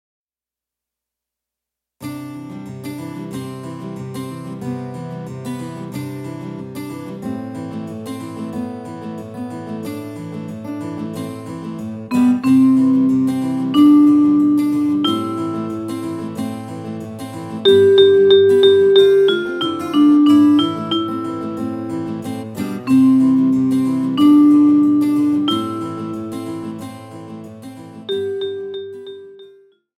Recueil pour Clarinette